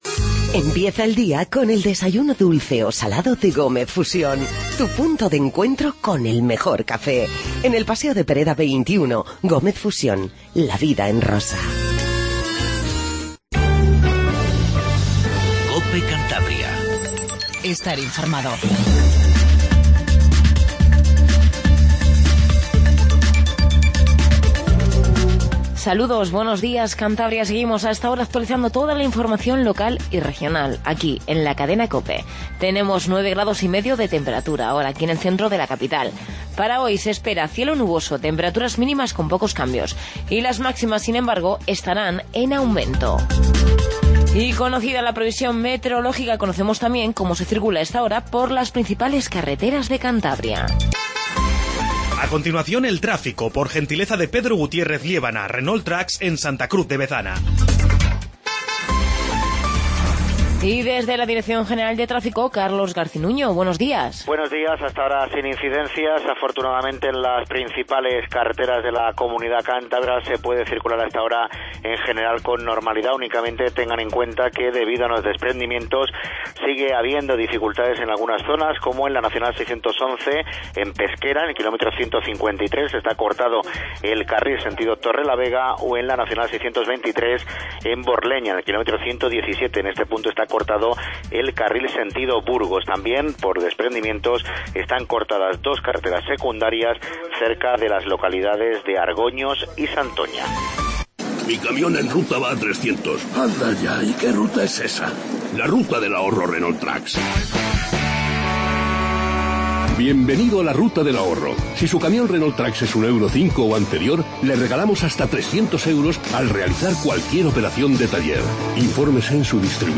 INFORMATIVO MATINAL 07:50